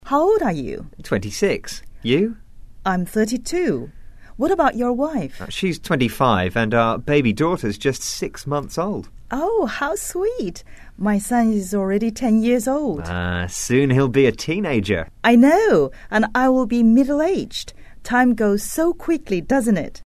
英语初学者口语对话第74集：谈论年龄
english_11_dialogue_1.mp3